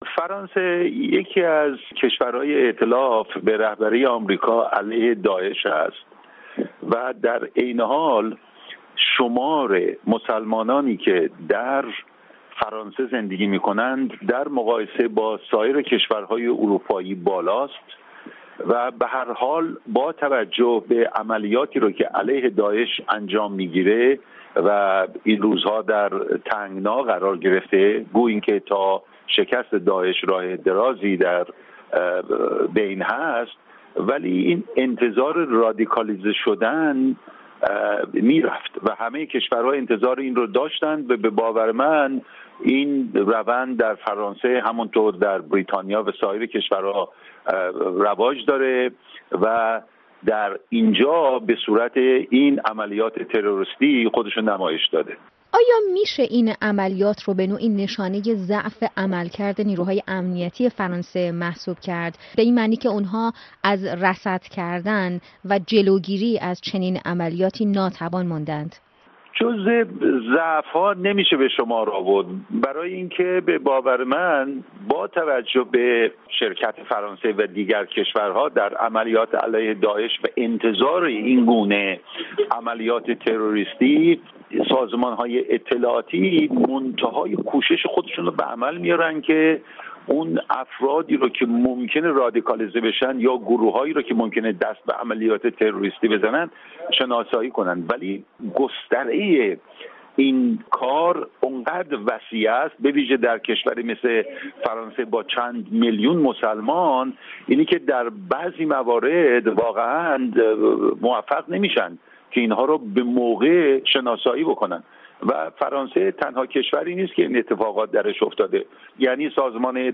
تحلیل